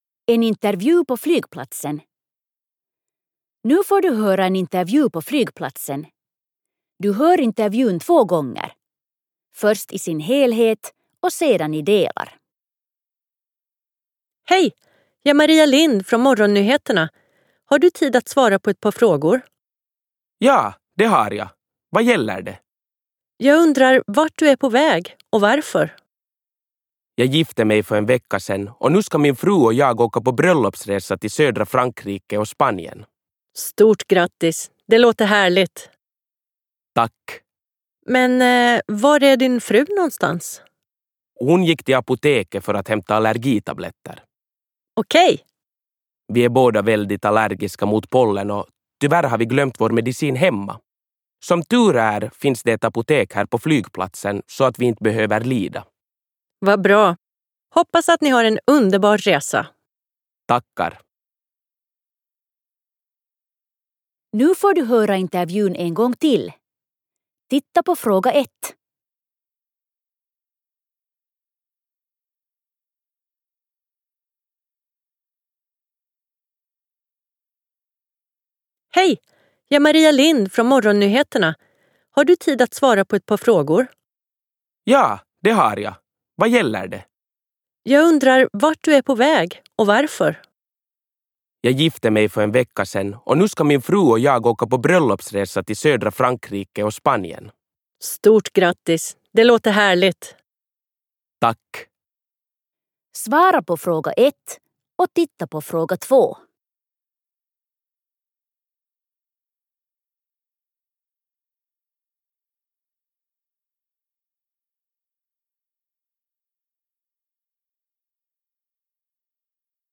Avokysymykset suomeksi Kuuntele haastattelu ja vastaa kysymyksiin suomeksi toisen kuuntelukerran aikana.
20_Resor_Intervu_flygplatsen_2.mp3